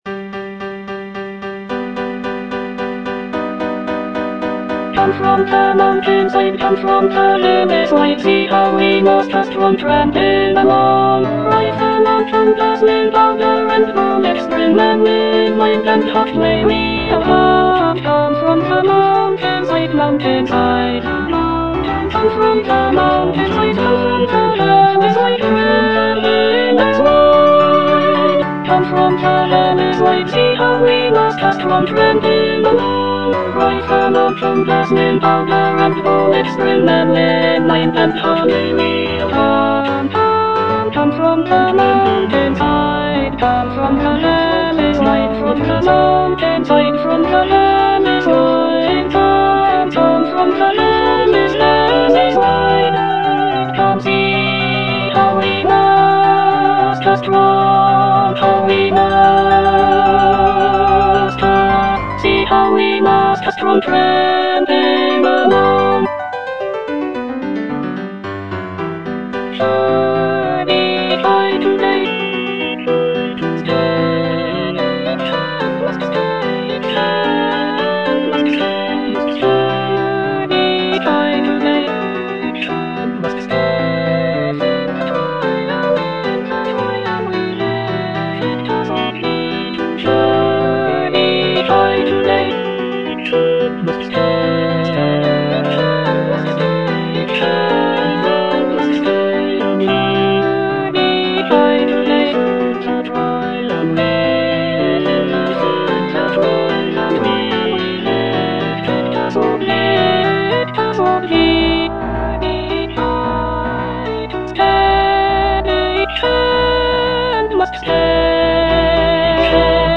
E. ELGAR - FROM THE BAVARIAN HIGHLANDS The marksmen (alto I) (Emphasised voice and other voices) Ads stop: auto-stop